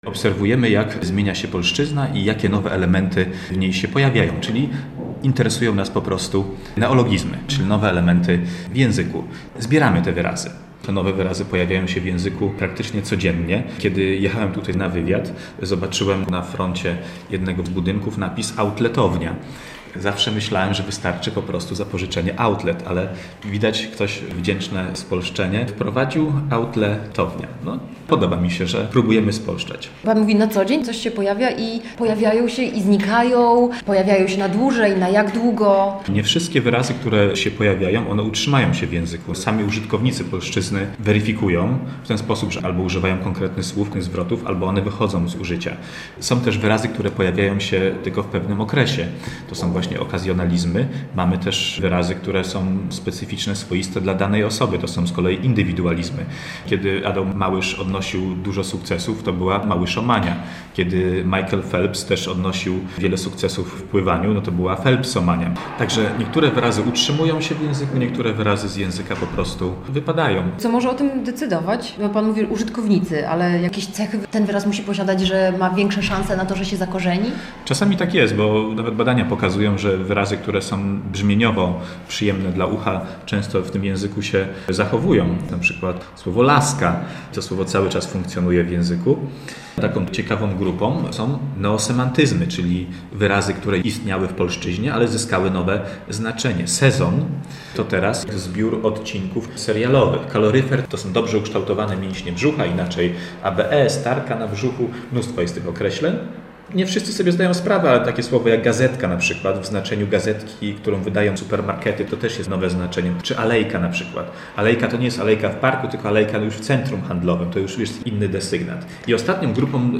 Z językoznawcą